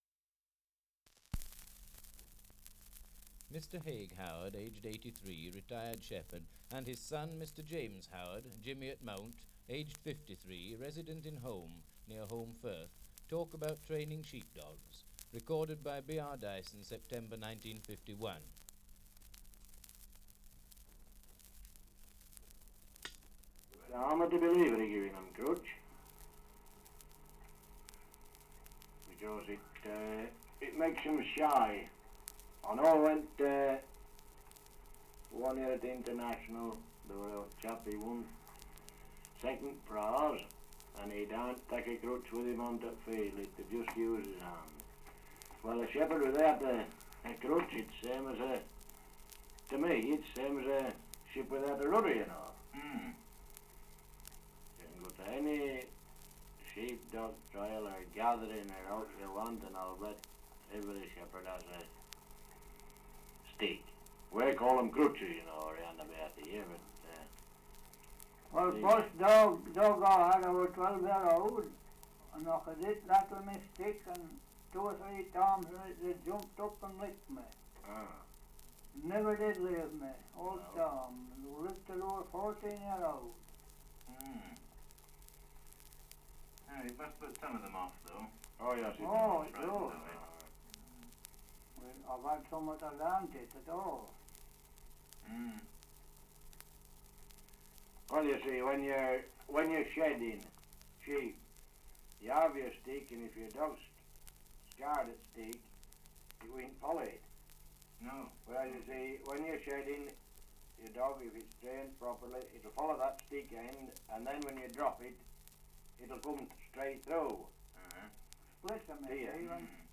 Title: Survey of English Dialects recording in Holmbridge, Yorkshire
78 r.p.m., cellulose nitrate on aluminium